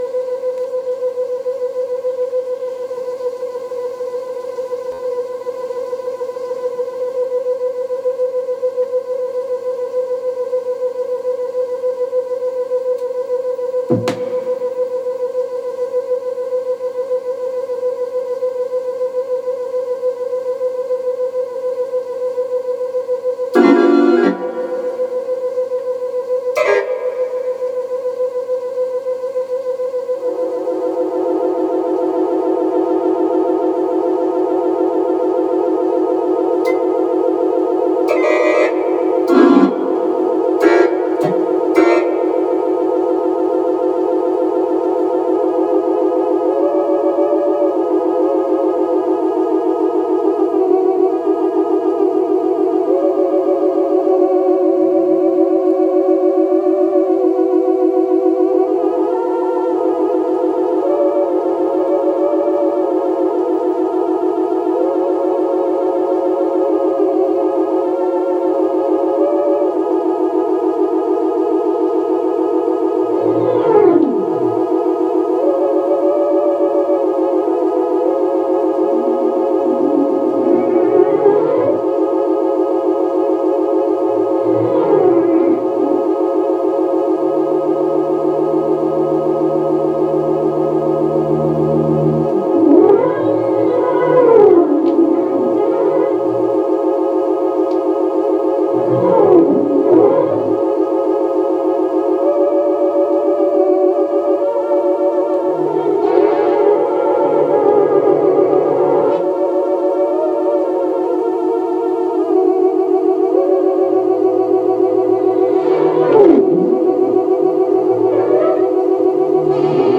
optical.sound.drawing